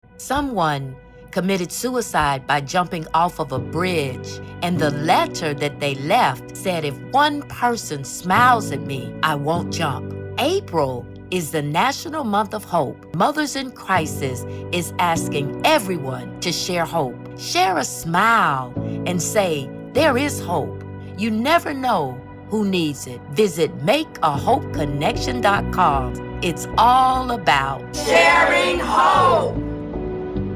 Radio-PSA-Its-All-About-Sharing-Hope.mp3